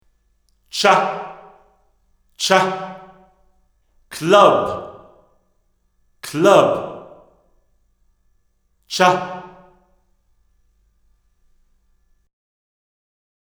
Tältä kaiut ja viiveet kuulostavat (muutin viiveajat Tap Tempo -toiminnalla):
Club
club.mp3